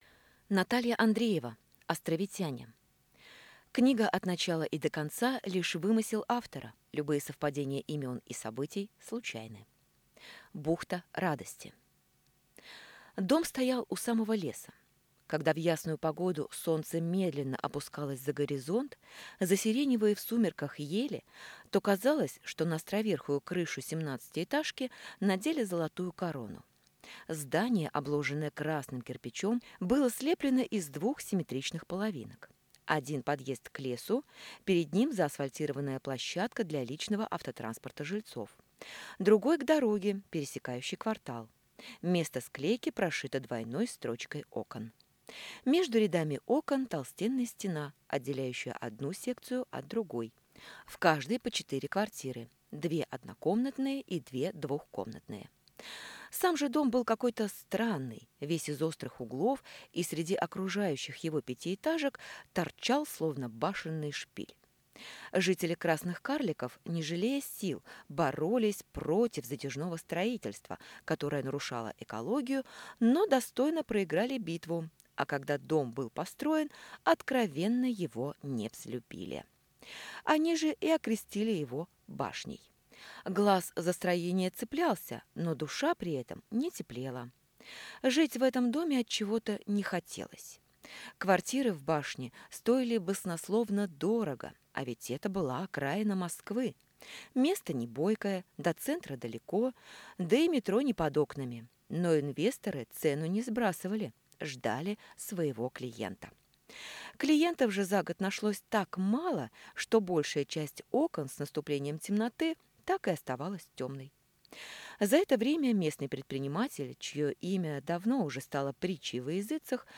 Аудиокнига Островитяне | Библиотека аудиокниг